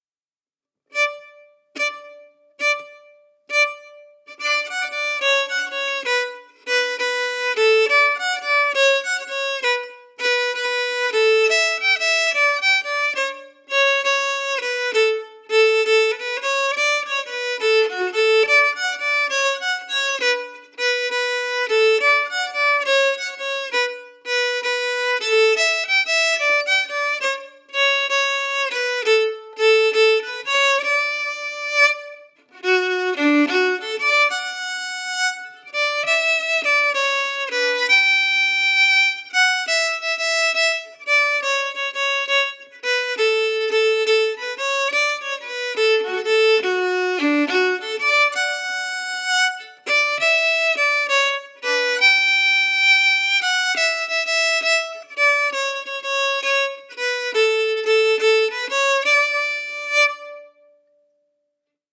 Key: D
Form: Québecois six-huit (Jig)
Genre/Style: Québecois six-huit
Quadrille-Bouchard-slow-audio.mp3